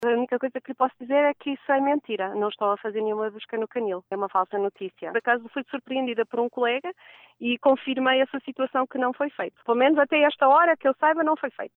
A Rádio Onda Livre realizou a chamada telefónica às 14h47, desta quinta-feira.